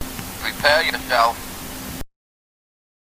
contribs)Ike's sound clip when selected with a Wii Remote.